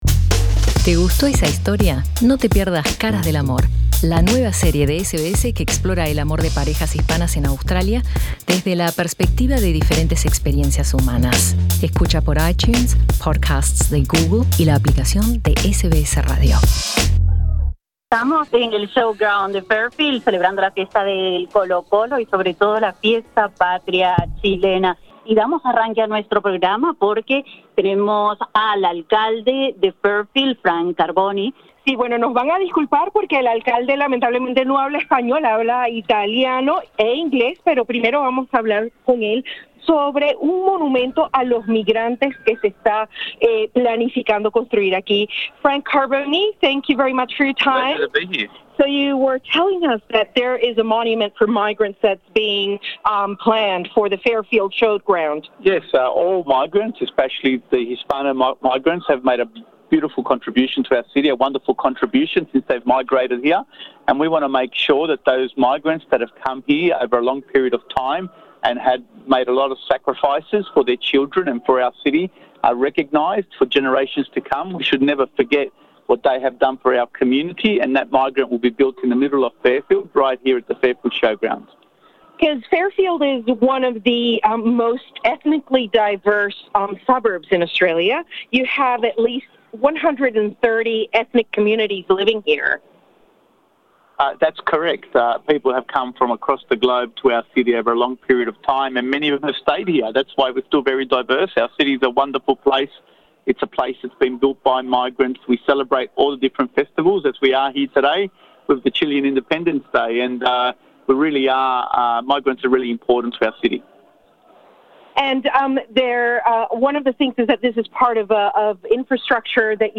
El alcalde del suburbio de Fairfield en Sídney, Frank Carbone, reveló a SBS Spanish que la municipalidad ha financiado un nuevo monumento para conmemorar la contribución de los inmigrantes y varios proyectos infraestructurales que mejoraran la vida de sus residentes.